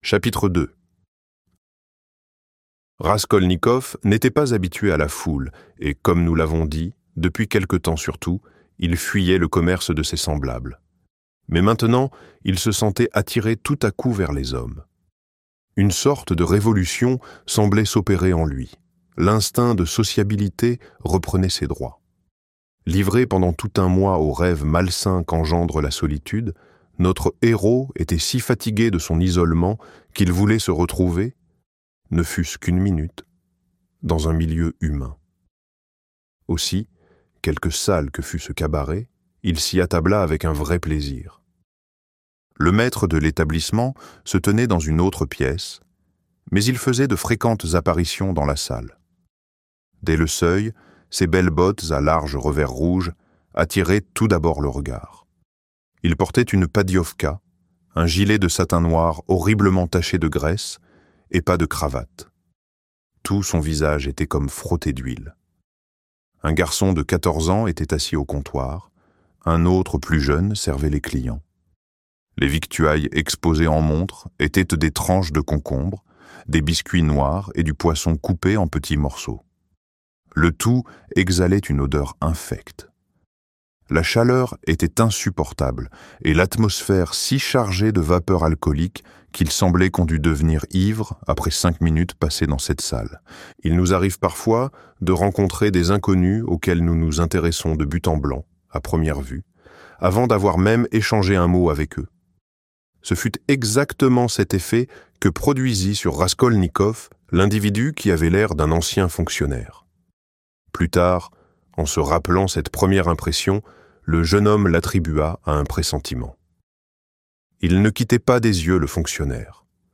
Crime et Châtiment - Livre Audio